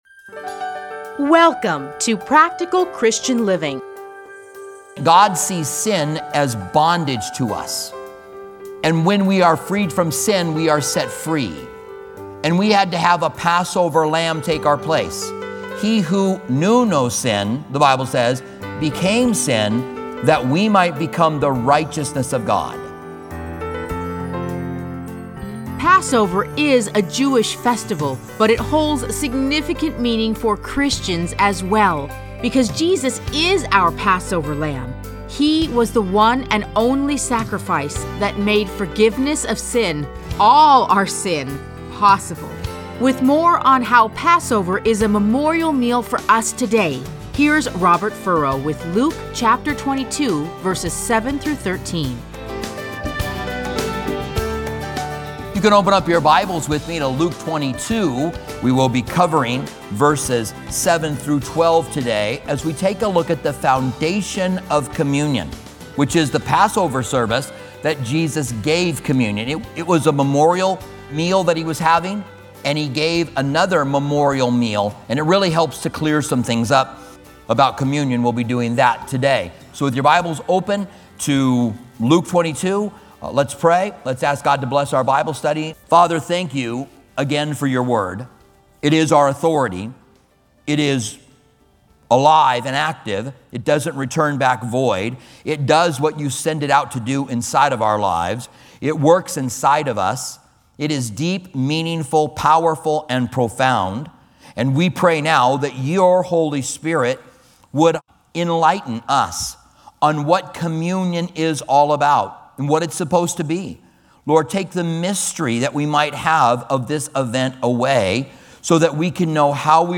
Listen to a teaching from Luke 22:7-13.